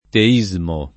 teismo [ te &@ mo ]